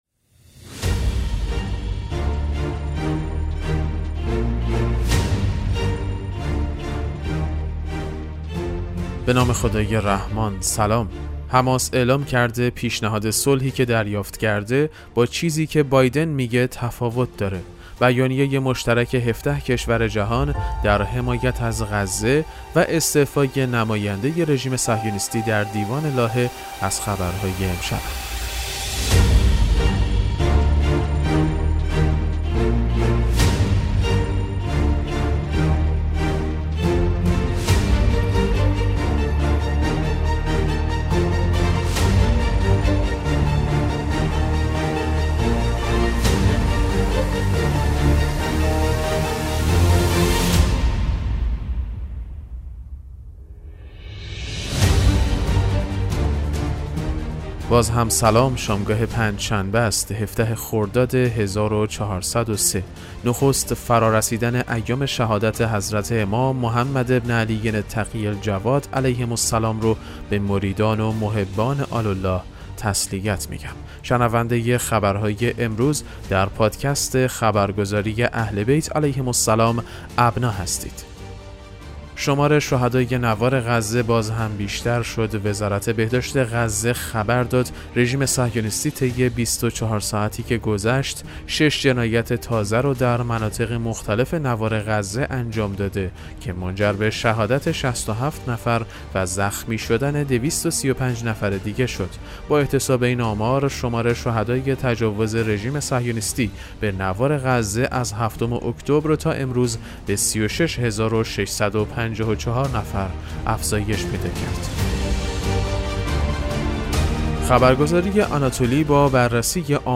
پادکست مهم‌ترین اخبار ابنا فارسی ــ 17 خرداد 1403